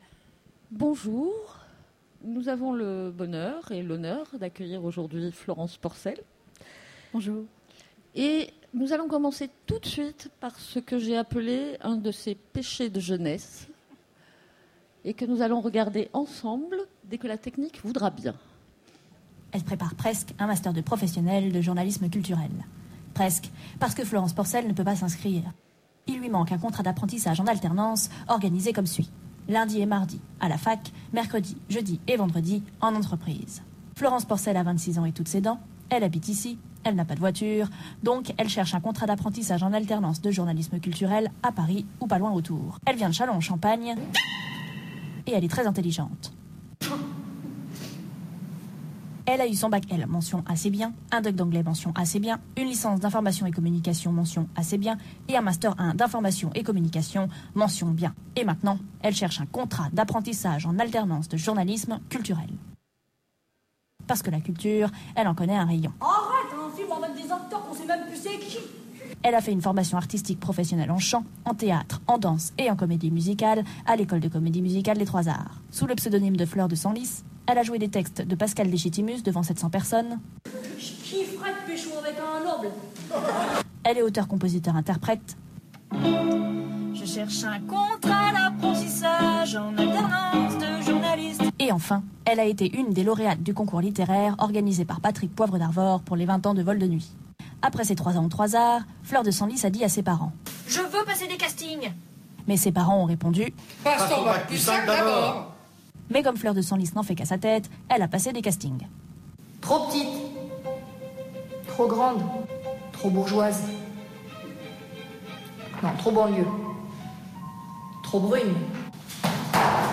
Rencontre avec un auteur Conférence